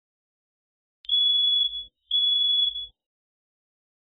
Space Station Alarm
Sci-Fi
Space Station Alarm is a free sci-fi sound effect available for download in MP3 format.
430_space_station_alarm.mp3